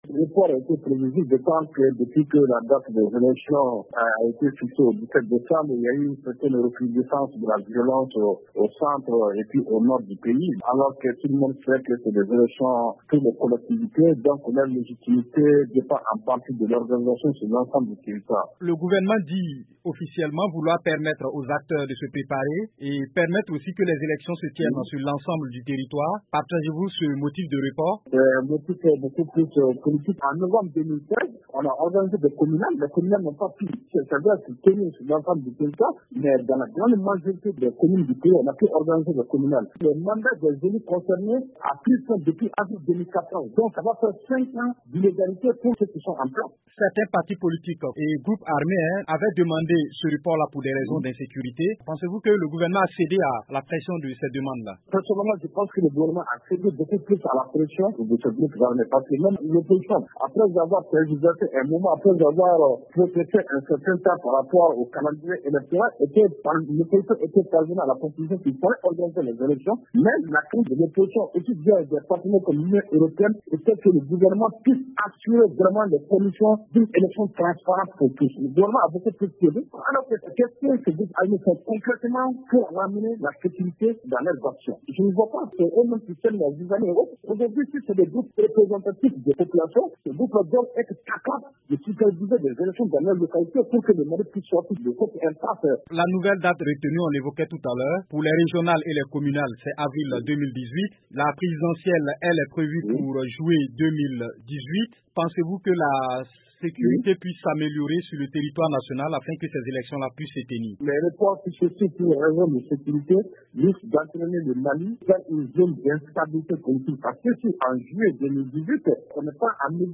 journaliste indépendant